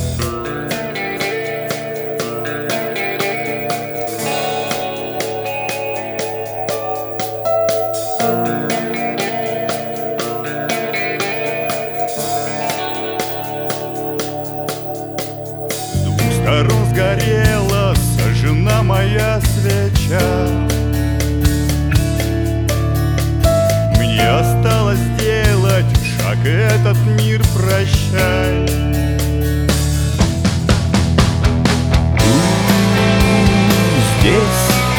Жанр: Рок / Русские